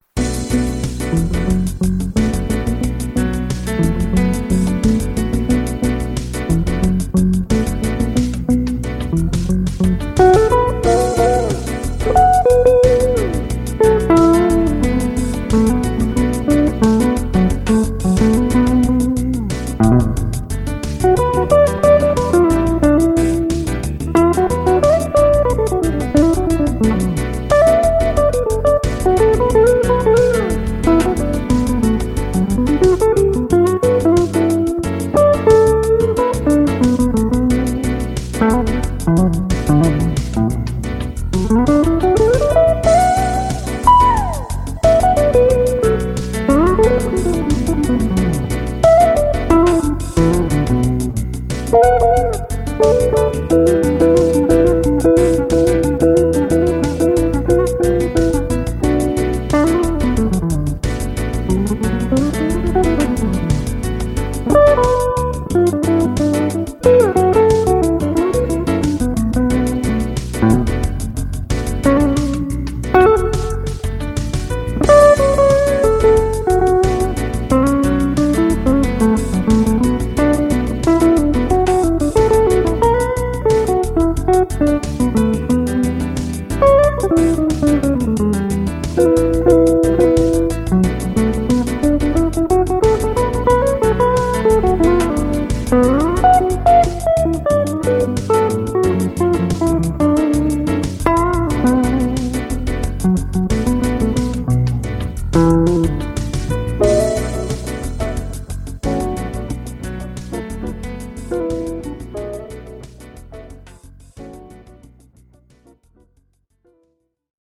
converted midi tracks
recorded my guitar parts
a recent solo